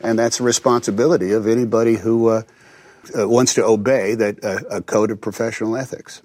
obey /əʊˈbeɪ/ → /əˈbeɪ/
First, American actor Tom Hanks, who keeps the diphthong and pronounces /əʊˈbeɪ/.